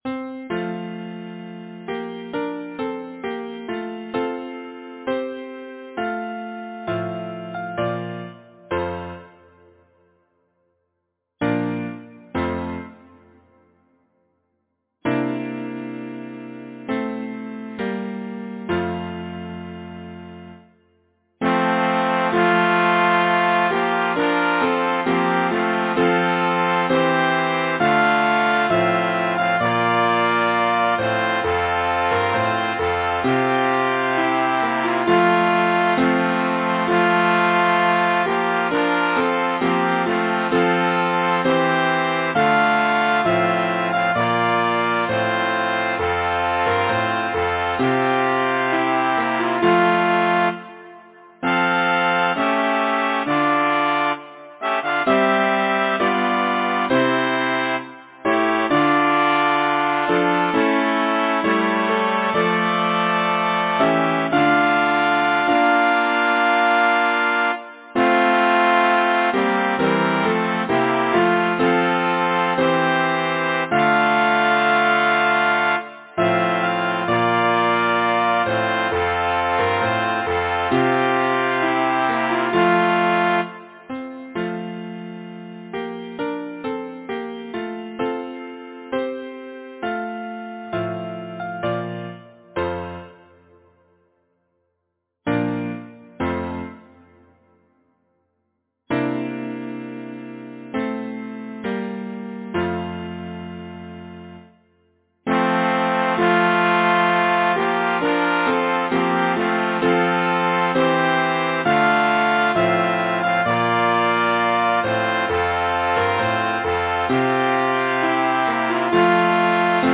Title: The minstrel boy Composer: Anonymous (Traditional) Arranger: Michael William Balfe Lyricist: Thomas Moore Number of voices: 4vv Voicing: SATB Genre: Secular, Partsong, Folksong
Language: English Instruments: Piano